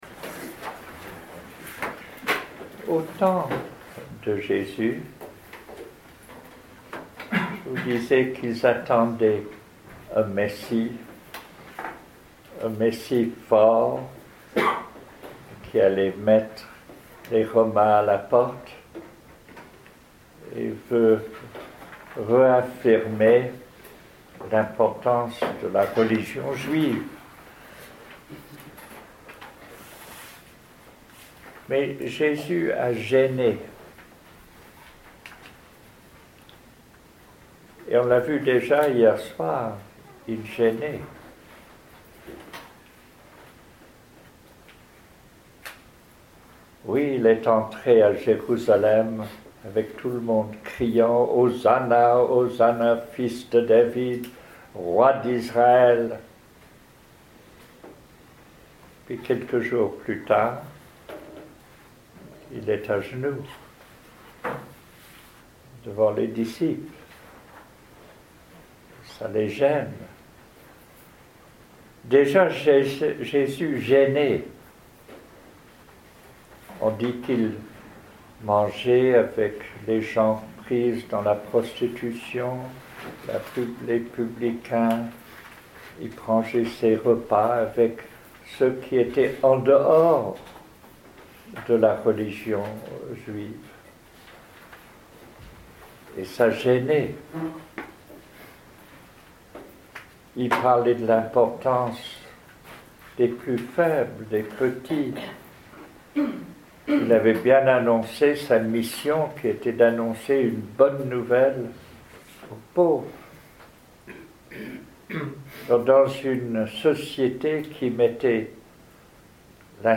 Week-end avec Jean Vanier, à l’Arche, à Trosly-Breuil (Oise), en juin 2014